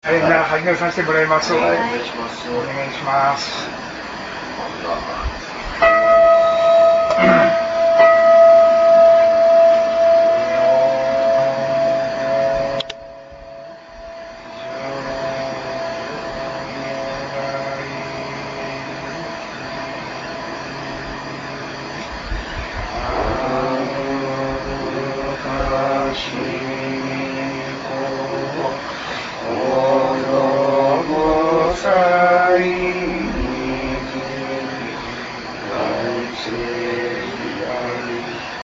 しかも、ここの在所は、お宅のお座敷を順番に巡る形式でつとめられます。
家の当主が前に出てお調子を取り、
あとの人はその家の節に合わせて唱和します。
お講さまのおつとめ（行譜正信偈六首引き）　「帰命無量寿如来〜」　(mp3 300KB)